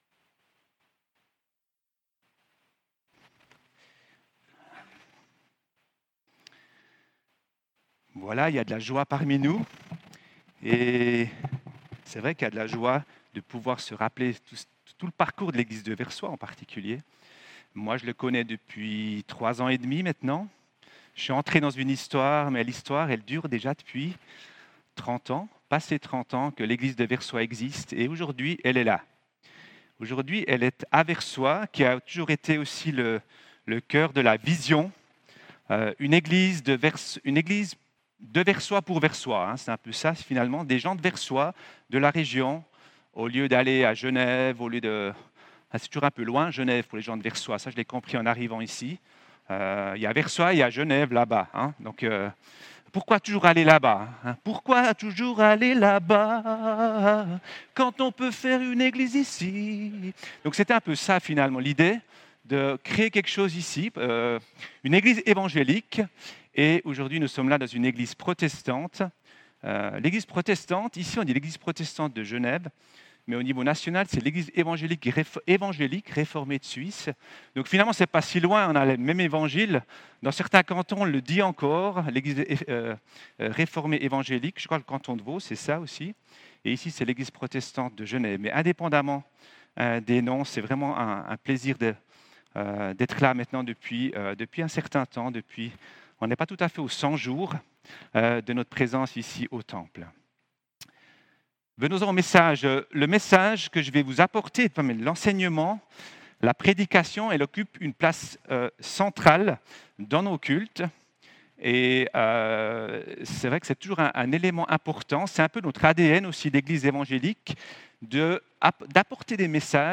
Culte inauguration et table ronde